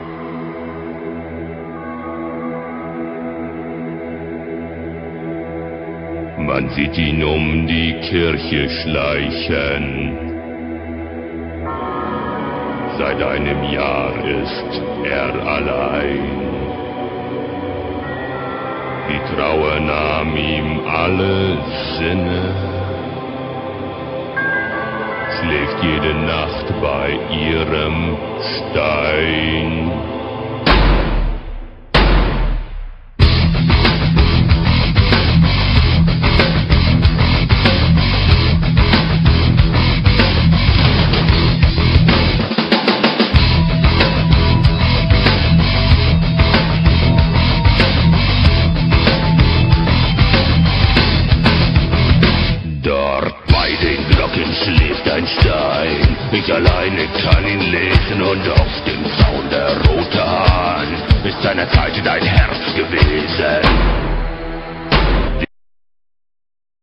metal
they have a heavy, machine like rythem
and growling vocals.